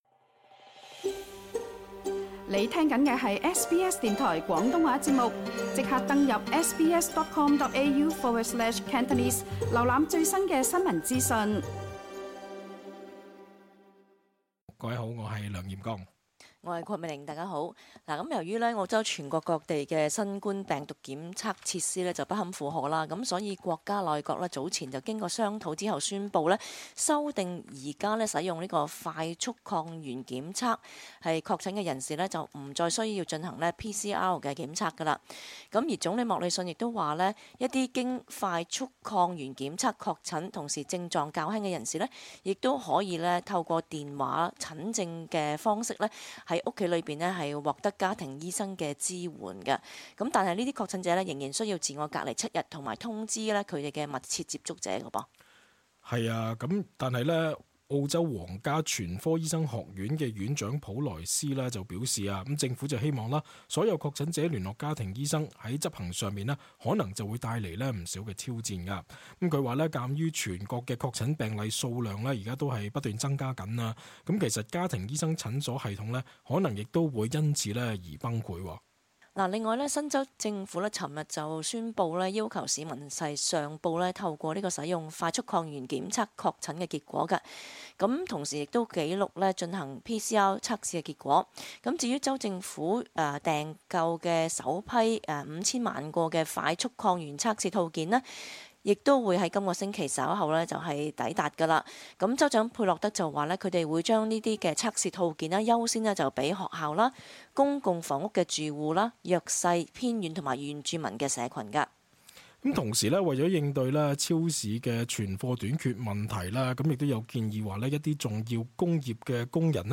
cantonese_talkback_-_jan_13_-_drupal.mp3